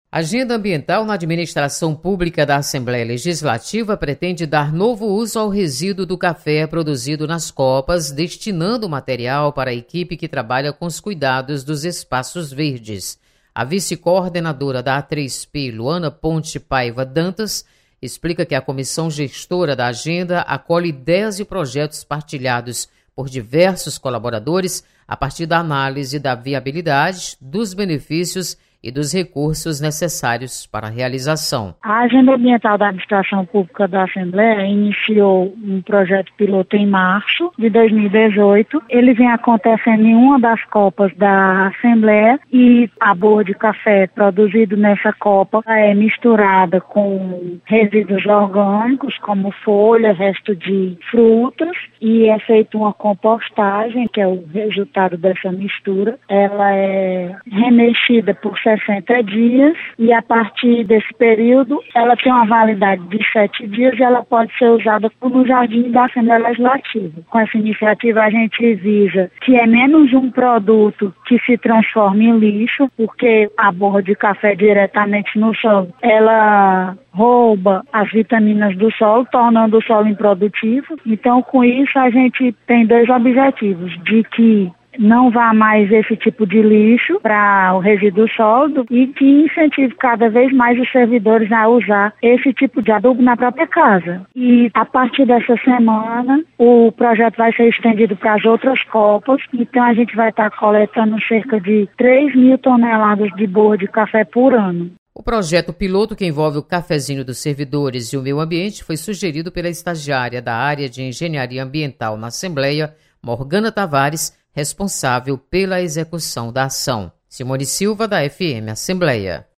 Adotada nova destinação ao resíduo do café produzido nas copas da Assembleia Legislativa. Repórter